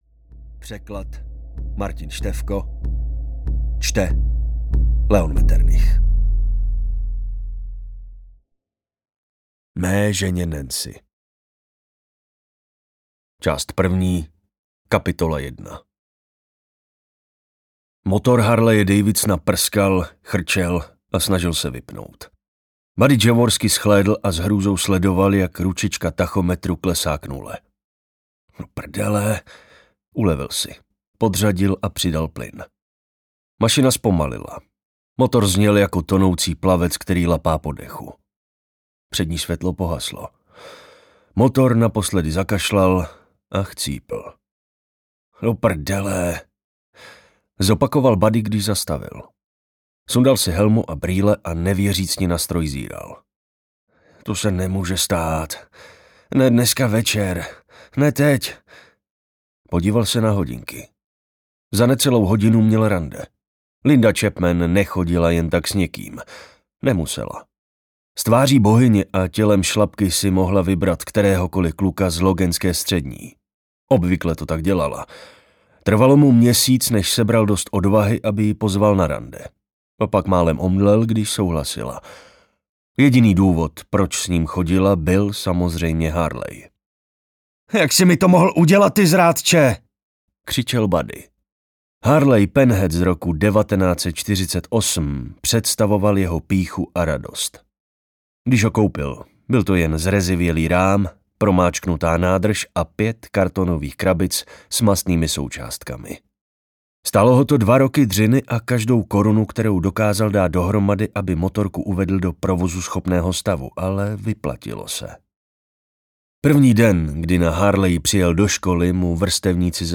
Crota audiokniha
Ukázka z knihy